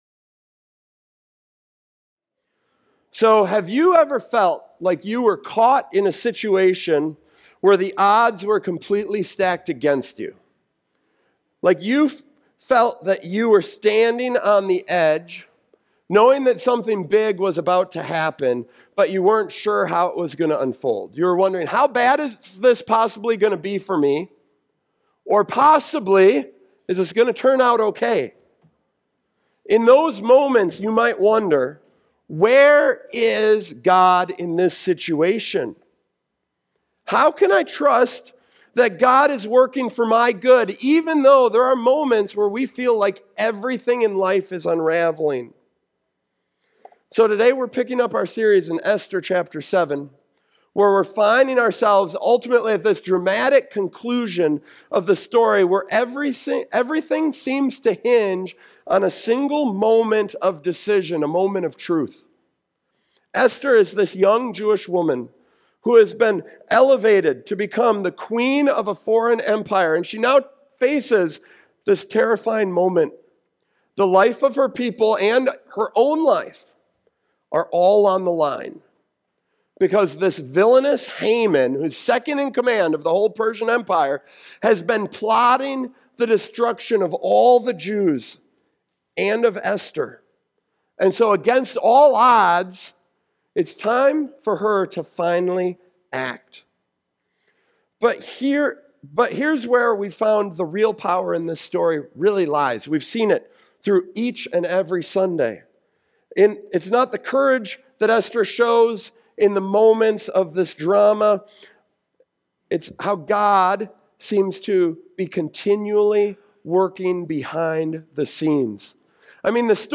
Sovereignty Esther and the Hidden Hand of God Esther Watch Listen Read Save The sermon focuses on Esther chapter 7, the dramatic climax of the story, where Esther courageously intercedes for her people despite immense risk.